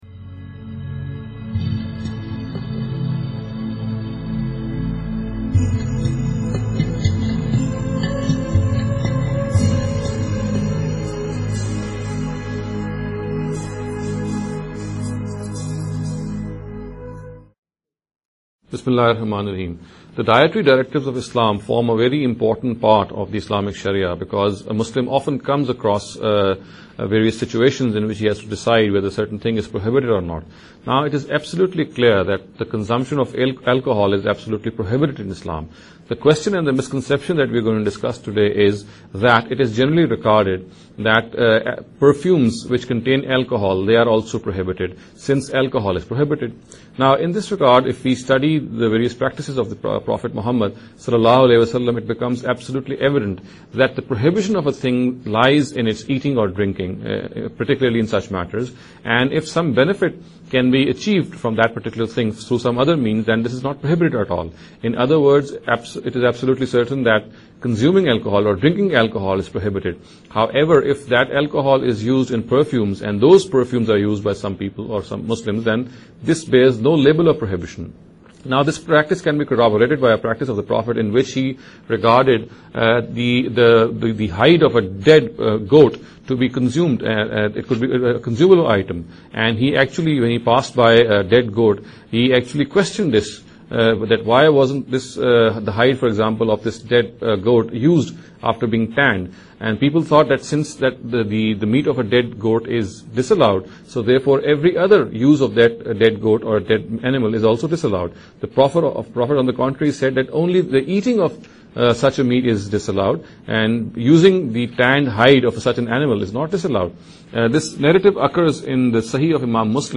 This lecture series will deal with some misconception regarding the Islam and Jihad.